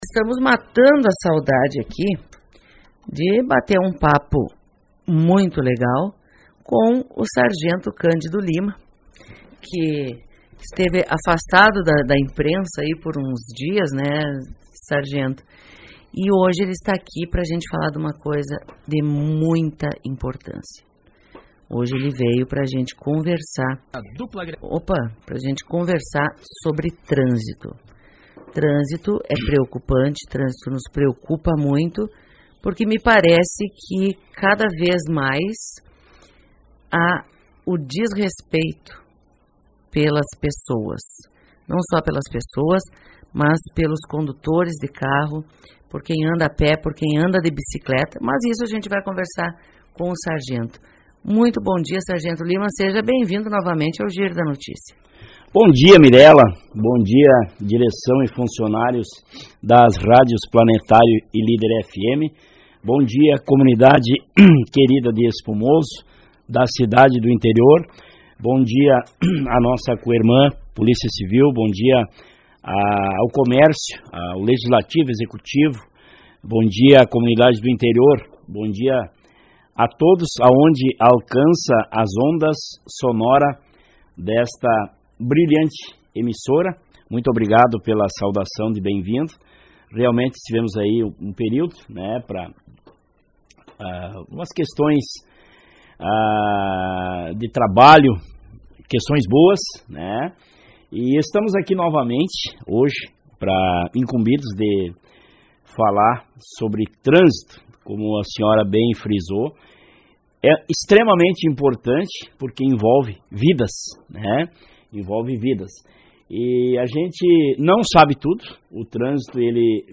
Entrevista: Trânsito Seguro é preocupação da Brigada Militar de Espumoso